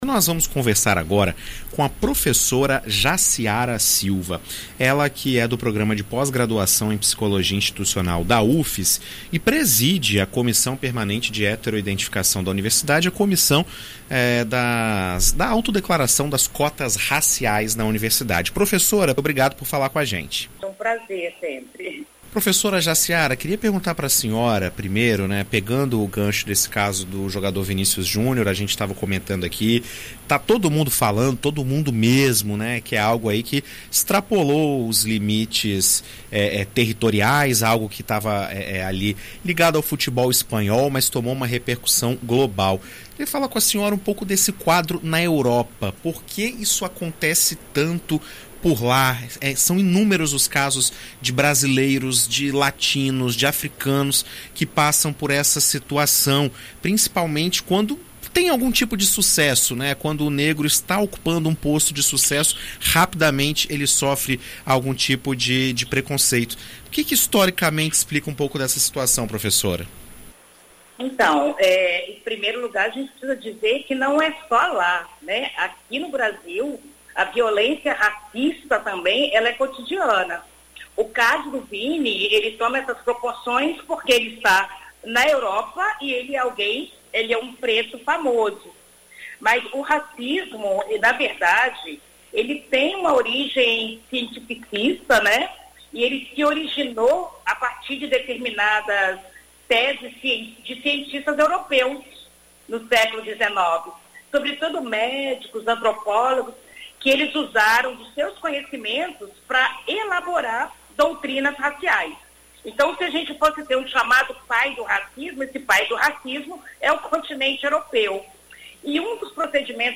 Em entrevista à BandNews FM ES nesta segunda-feira (22)